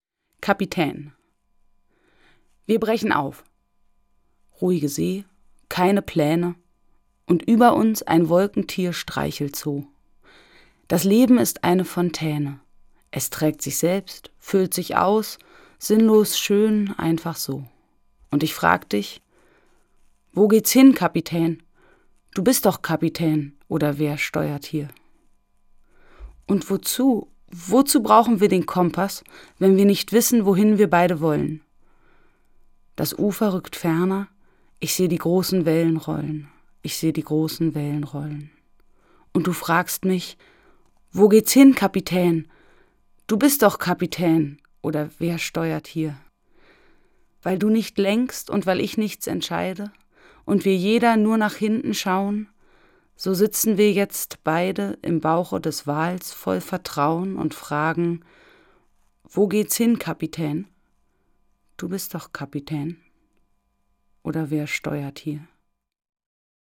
Das radio3-Gedicht der Woche: Dichter von heute lesen radiophone Lyrik.
Gelesen von Dota Kehr.